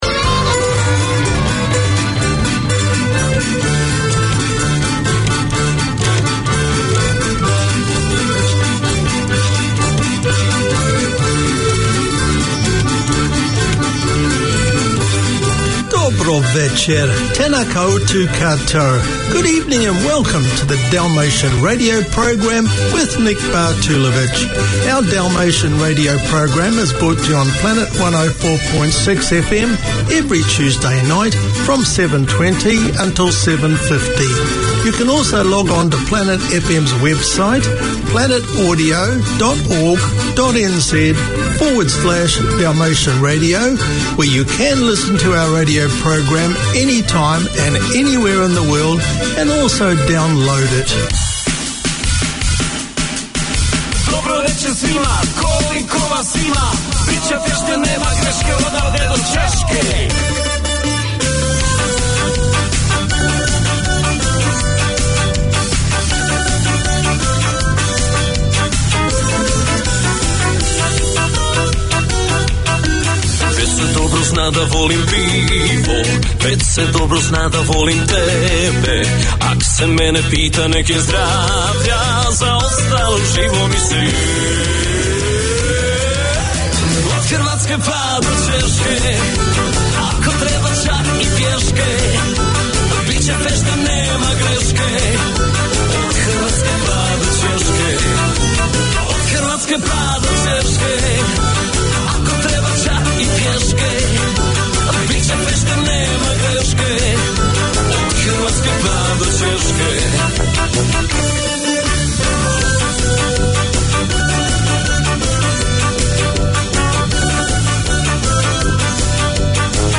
We present Society news and explore the achievements of Kiwis of Dalmatian descent. The music selected from around the former Yugoslavia is both nostalgic and modern.